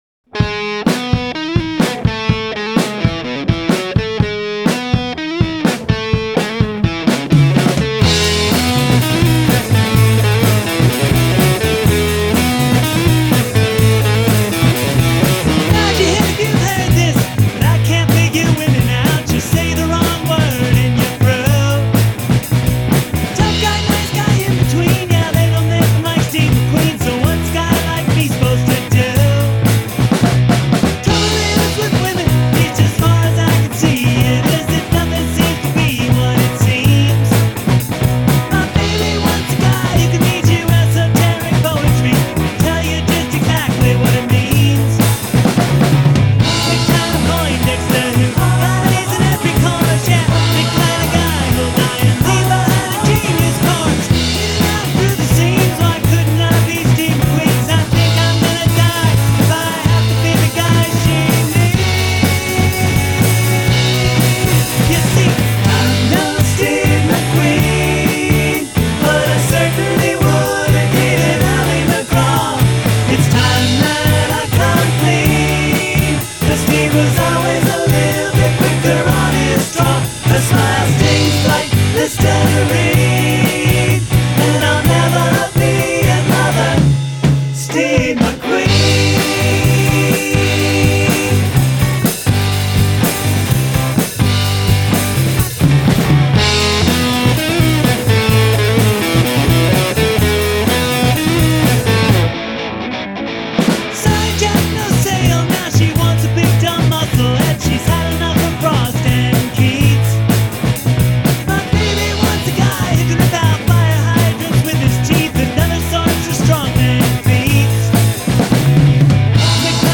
But I'm working on my guitar and vocal sound and I figure why not just screw up one song over and over instead of screwing up a bunch of songs. Anyway, I have a new guitar on this one.